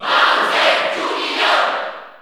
Crowd cheers (SSBU) You cannot overwrite this file.
Bowser_Jr._Cheer_Italian_SSB4_SSBU.ogg